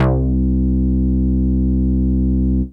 ARP BASS 1.wav